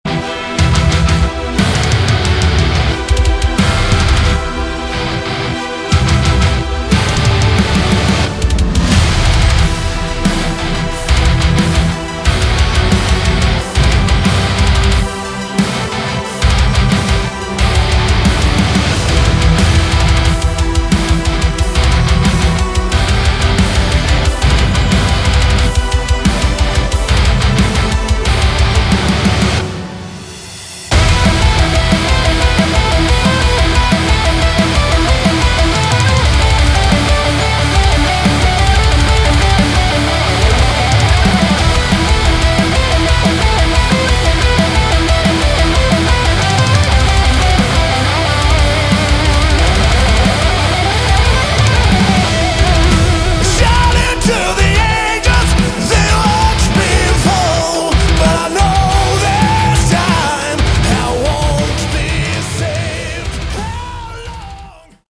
Category: Melodic Metal
vocals, guitars
bass
drums